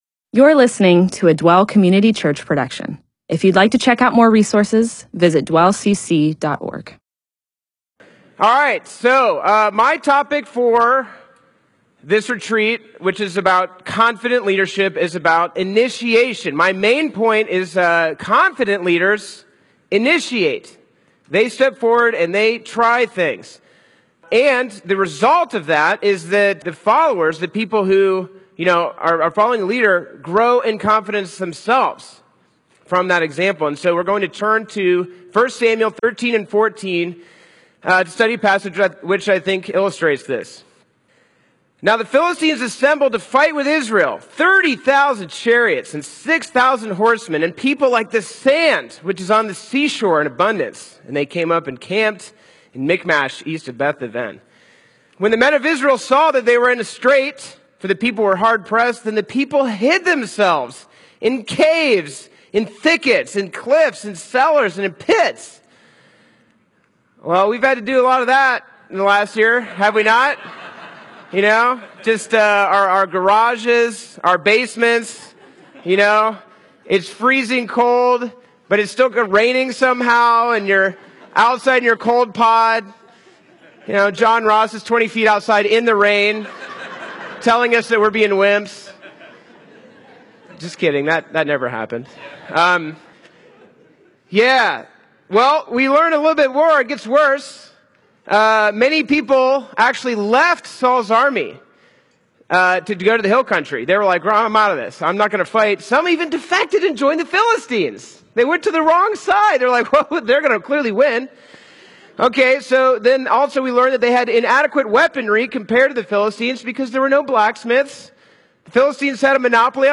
MP4/M4A audio recording of a Bible teaching/sermon/presentation about 1 Samuel 13-14.